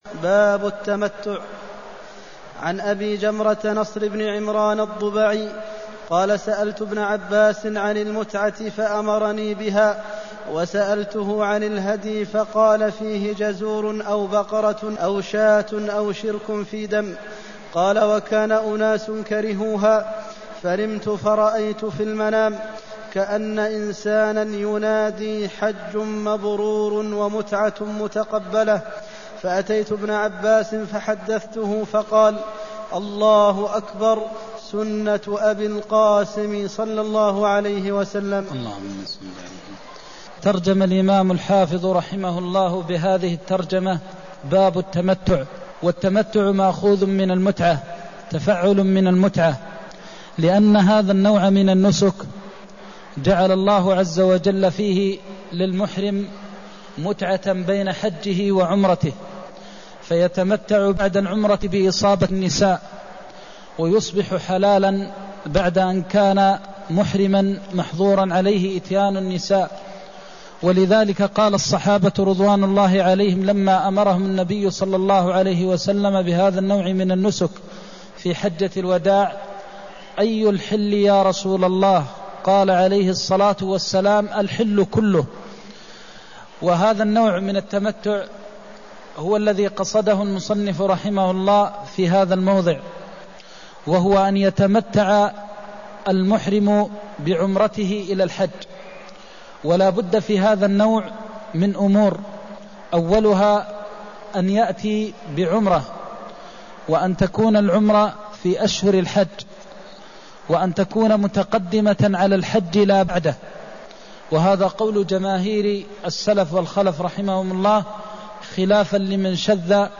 المكان: المسجد النبوي الشيخ: فضيلة الشيخ د. محمد بن محمد المختار فضيلة الشيخ د. محمد بن محمد المختار سألت ابن عباس عن المتعة فأمرني بها (220)